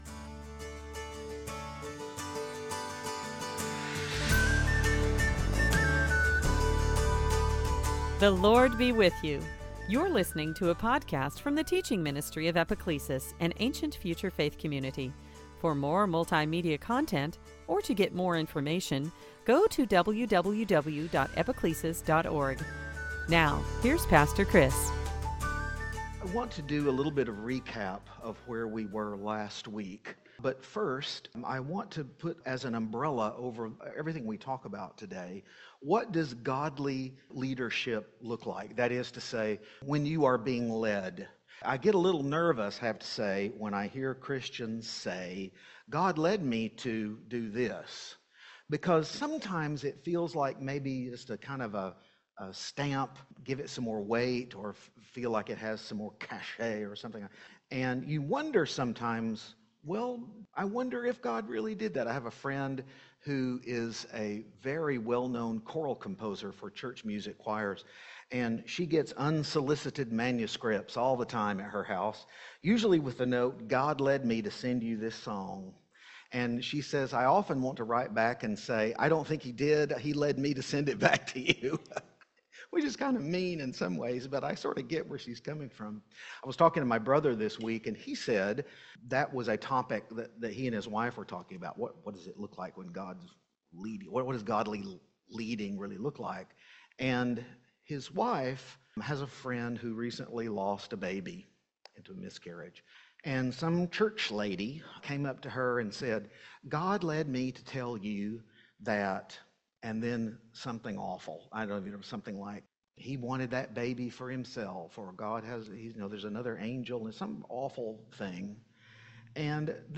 Luke 4:1-30 Service Type: Epiphany Have you ever heard a Christian throw out the line